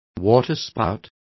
Complete with pronunciation of the translation of waterspouts.